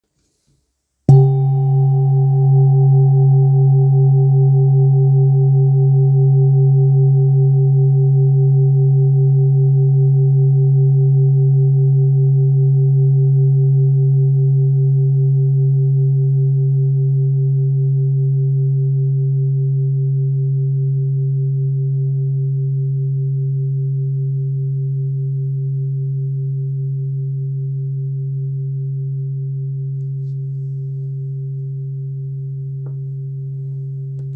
Kopre Singing Bowl, Buddhist Hand Beaten, Antique Finishing, Select Accessories
Material Seven Bronze Metal
It is accessible both in high tone and low tone .
In any case, it is likewise famous for enduring sounds. Kopre Antique Singing Bowls is accessible in seven different chakras tone.